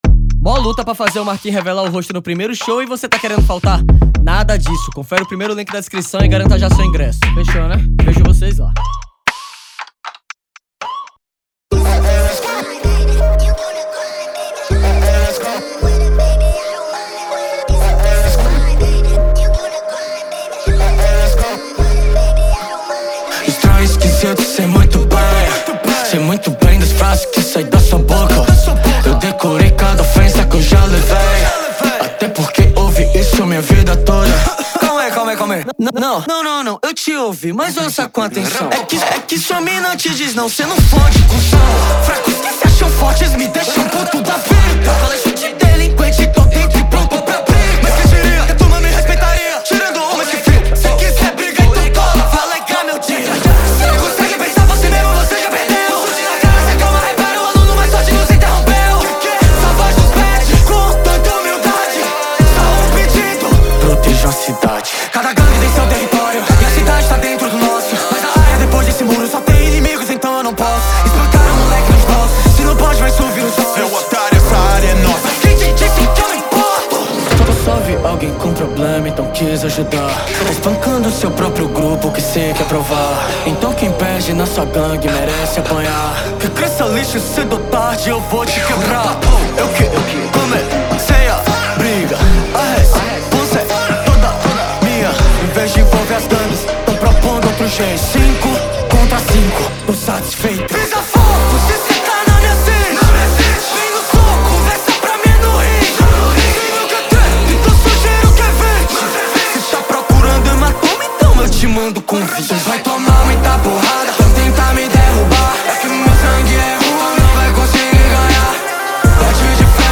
2025-02-23 15:34:57 Gênero: Rap Views